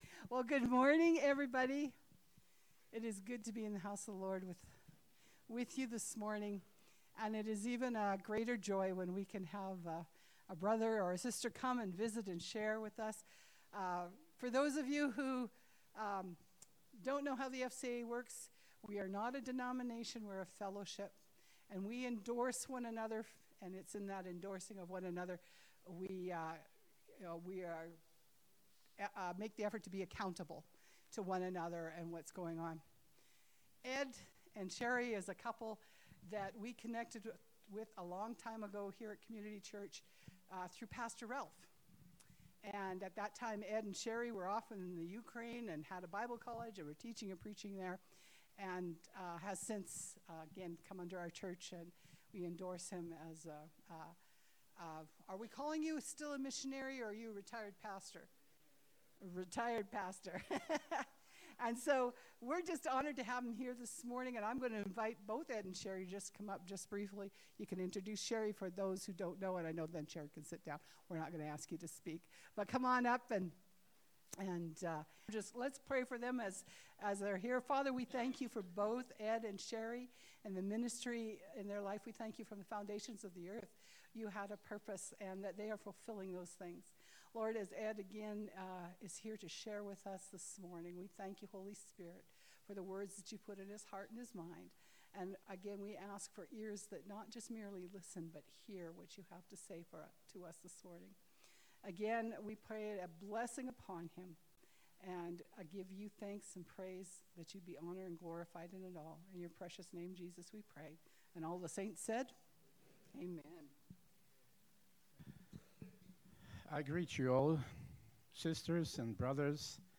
November-16-Sermon-Only.mp3